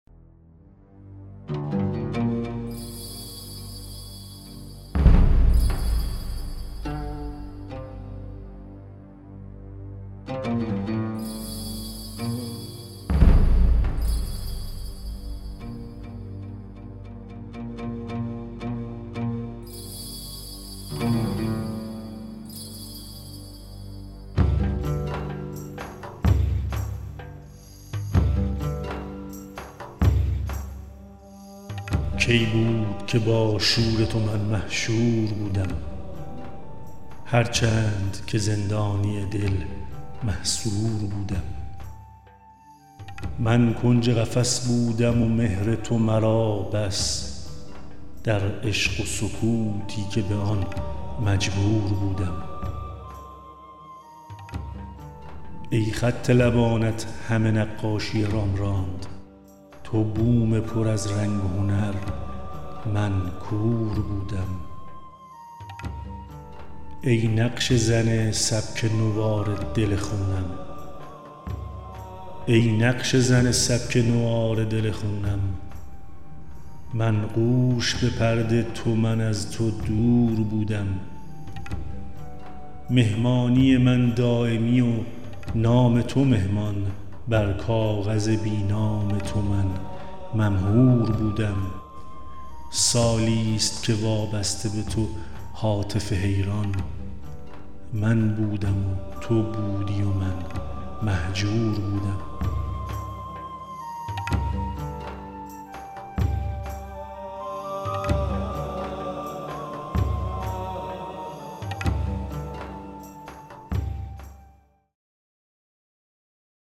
پادکست صوتی شعر قدیمی (غزل زندانی دل)